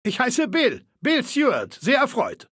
Datei:Maleold01 ms06 greeting 00027f96.ogg
Maleold01_ms06_greeting_00027f96.ogg (OGG-Mediendatei, Dateigröße: 23 KB.
Fallout 3: Audiodialoge